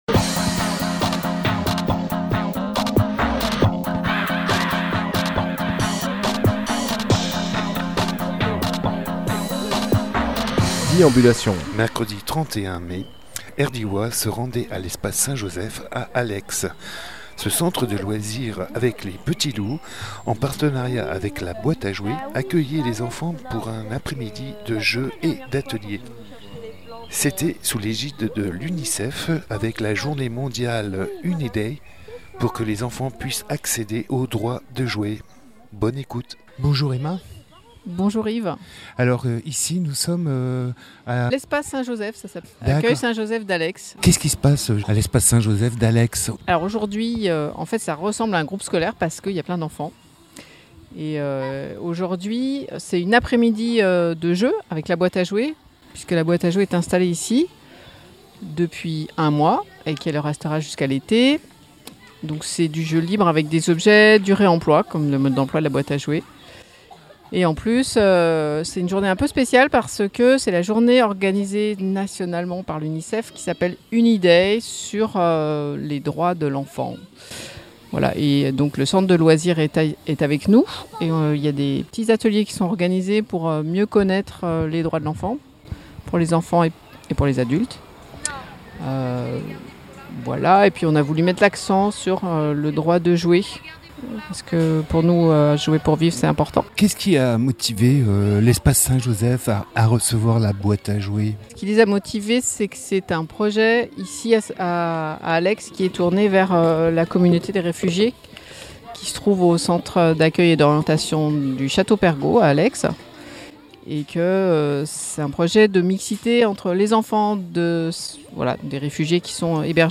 Emission - Reportages Jouer pour vivre à Allex Publié le 11 juin 2017 Partager sur… Télécharger en MP3 Mercredi 31 mai à l’Accueil Saint Joseph d’Allex , l’association Jouer pour vivre était en partenariat avec Les p’tits loups , association d’Allex et conjointement le CAO local, pour animer le “ Uniday ” une initiative de l’ UNICEF au droit de jouer pour les enfants.
Lieu : Allex